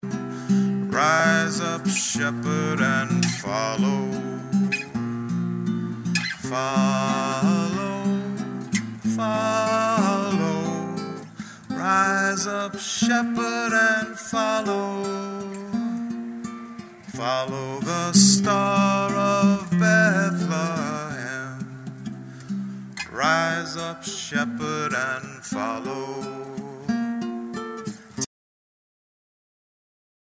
recorded IN CONCERT